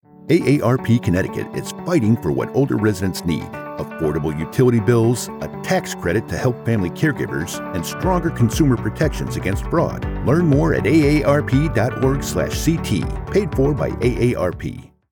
I have a deep, rich, resonant voice perfect for any type of voice over you need.
Online ad for AARP Authentic, Believable, Real person
A Source-Connect equipped professional Whisper Room sound booth.